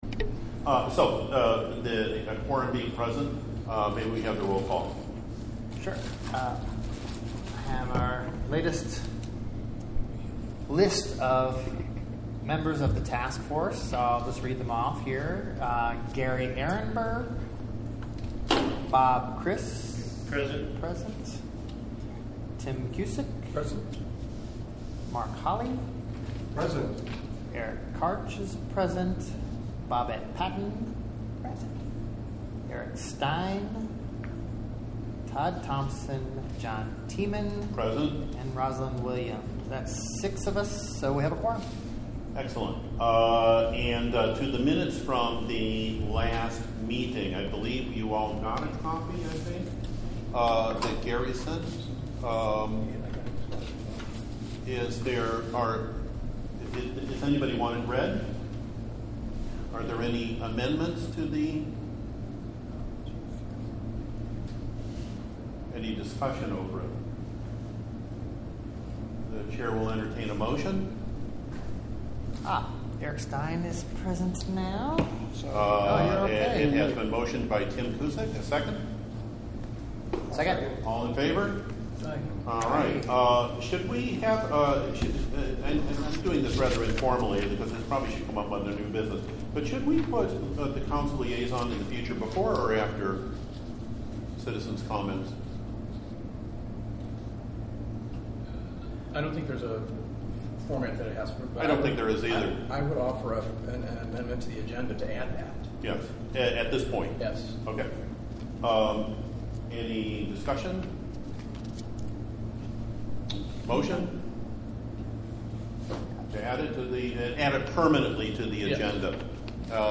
Stormwater Taskforce Meeting Audio 04-02-19 mp3